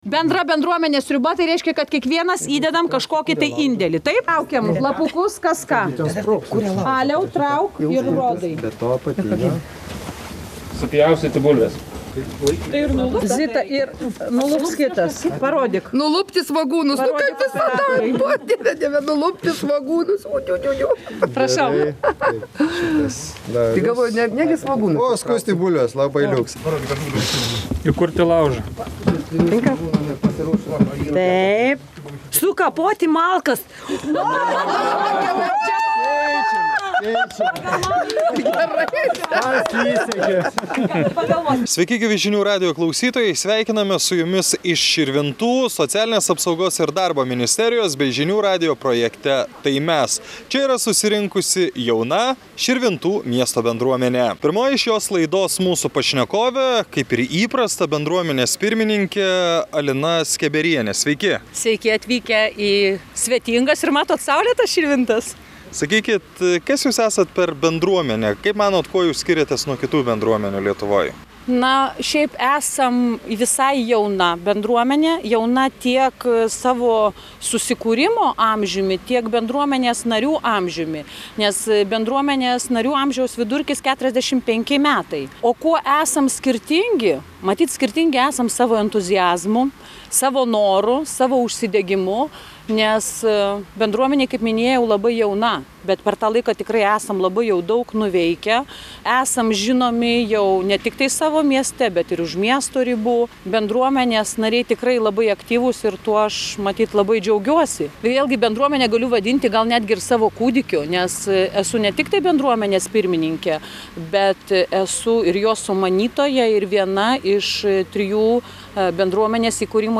Lankomės Širvintų miesto bendruomenėje.